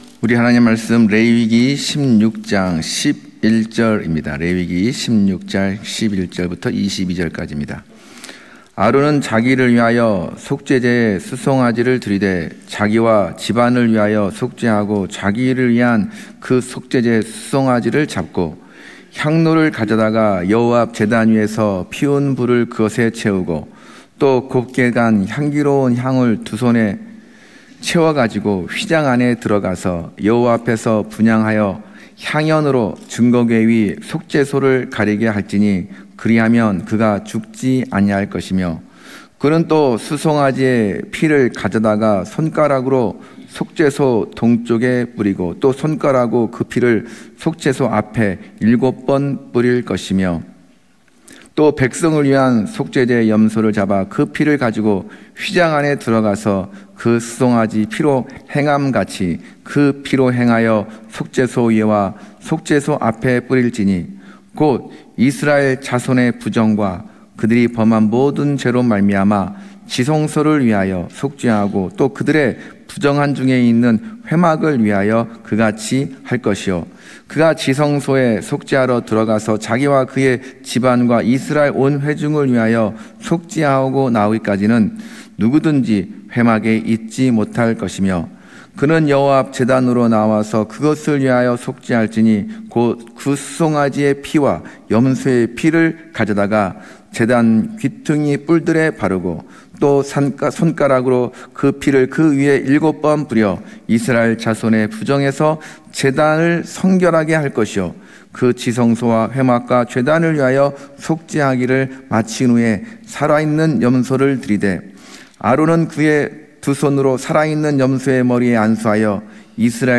금요설교